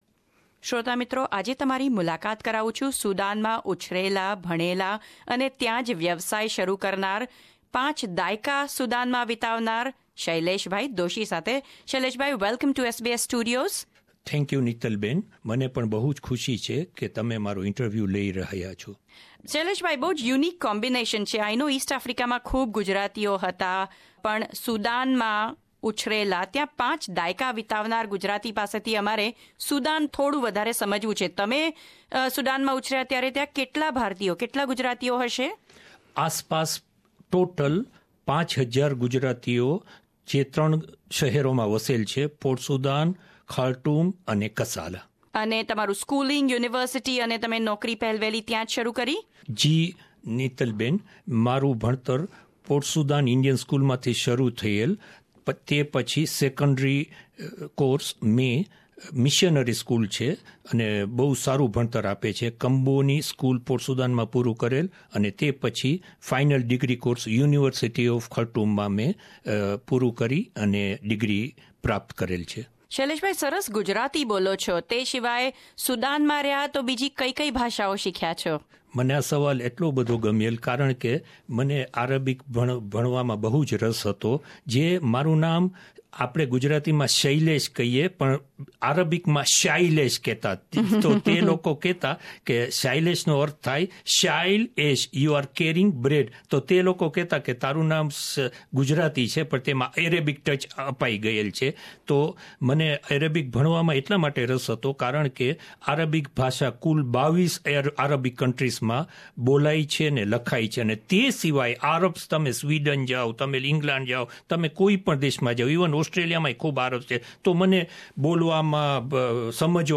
વાત-ચીત